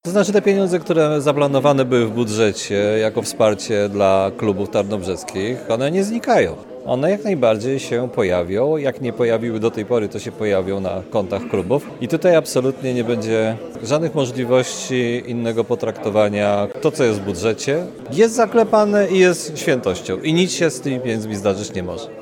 W Tarnobrzegu nie doszło do takiej sytuacji. Mówi prezydent miasta Dariusz Bożek.